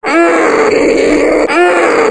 fast_zombie